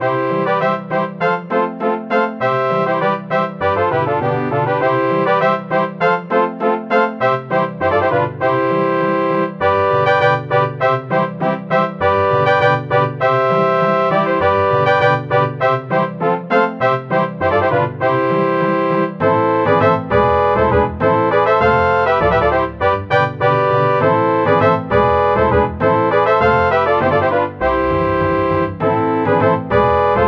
Converted from .mid to .ogg